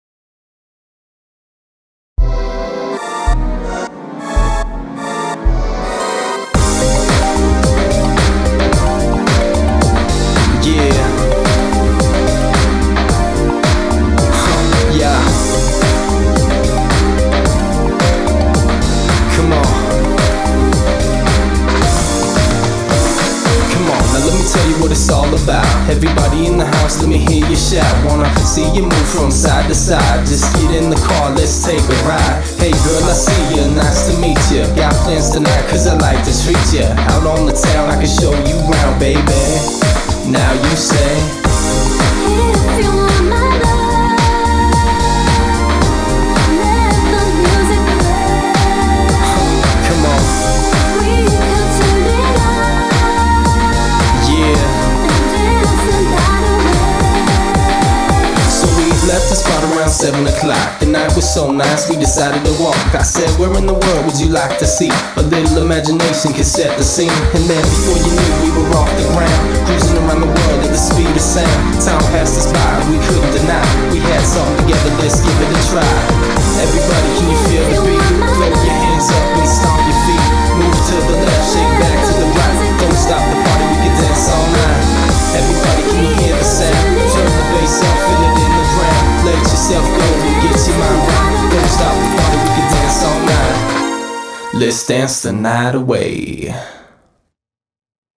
BPM110
Audio QualityPerfect (High Quality)